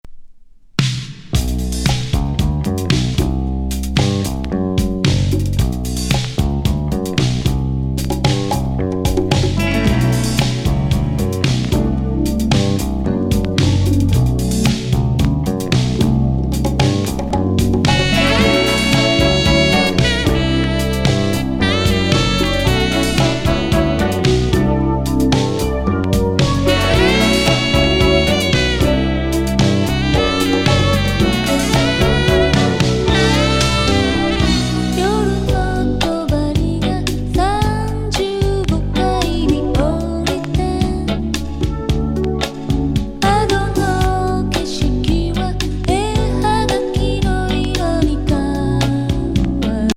エレクトリック・ディスコ歌謡
・メロウ・ソウル